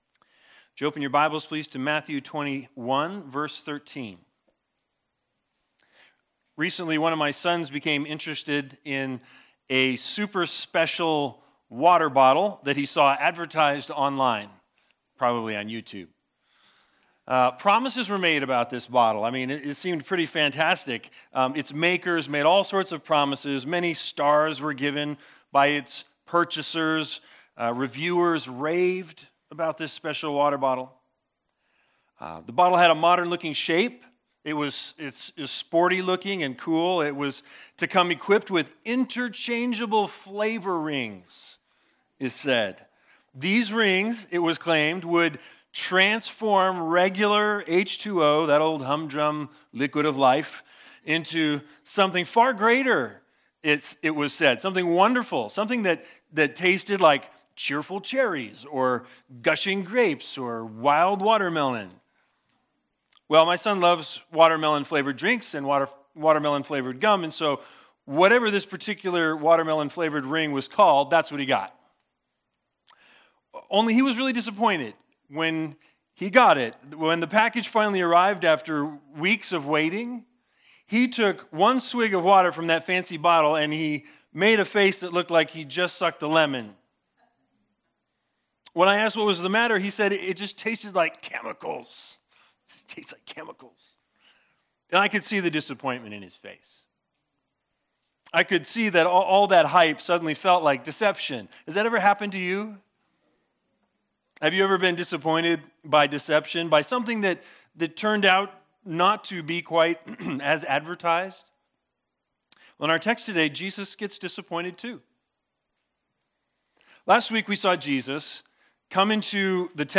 Matthew 21:18-22 Service Type: Sunday Sermons The Big Idea